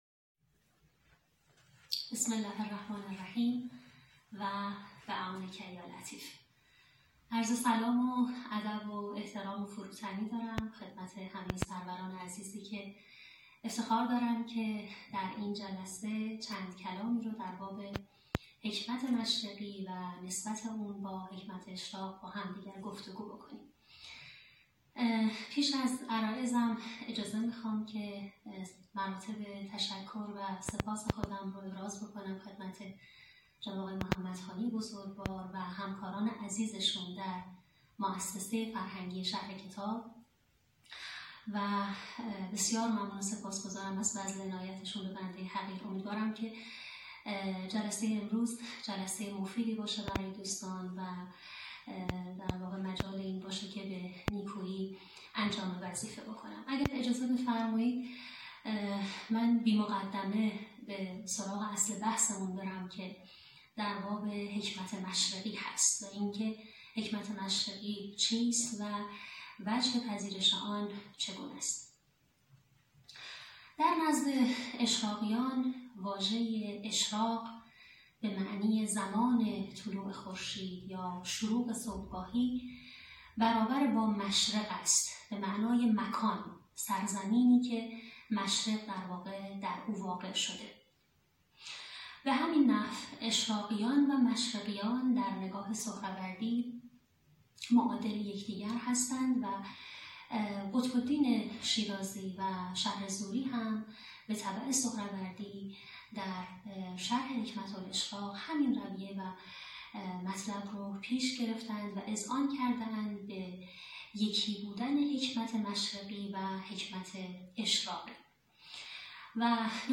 این درس‌گفتار به صورت مجازی از اینستاگرام شهر کتاب پخش شد.